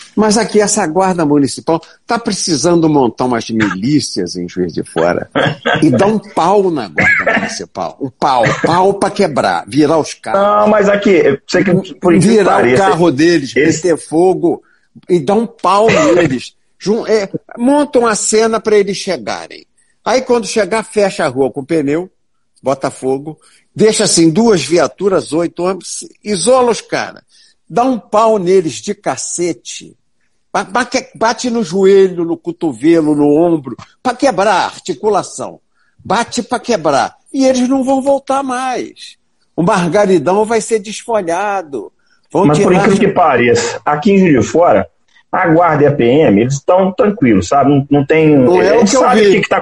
Ele participou de uma live realizada pelo vereador Sargento Mello Casal (PTB) no Instagram, com o objetivo de discutir o tratamento precoce para o coronavírus e a politização em torno da doença.